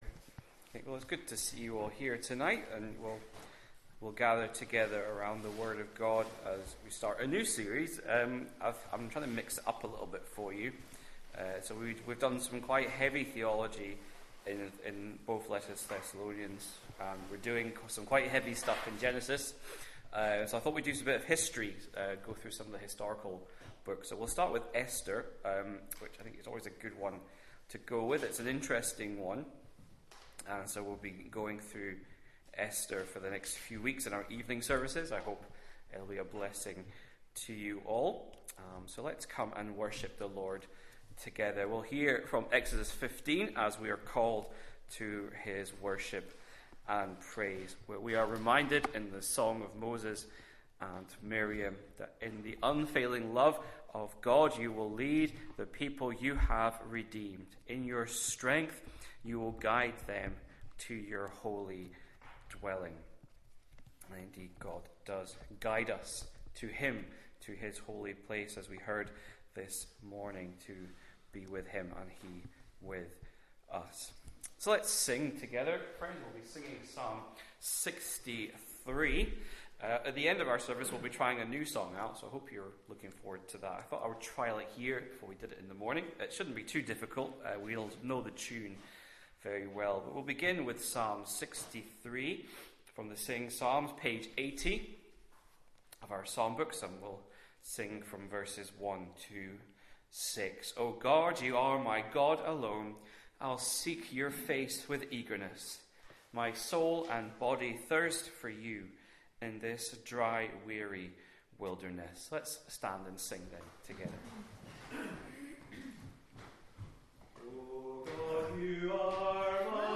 6.00pm Service